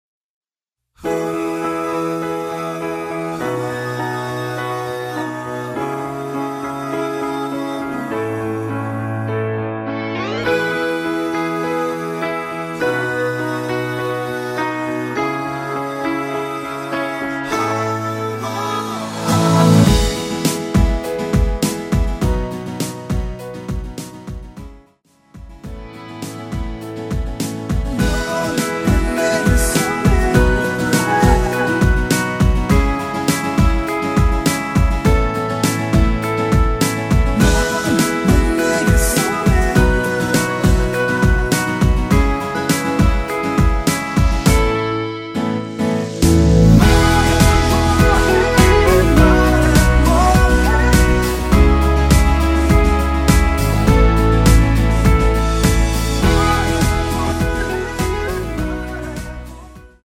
원키 코러스 포함된 MR 입니다.(미리듣기 참조)
앞부분30초, 뒷부분30초씩 편집해서 올려 드리고 있습니다.
중간에 음이 끈어지고 다시 나오는 이유는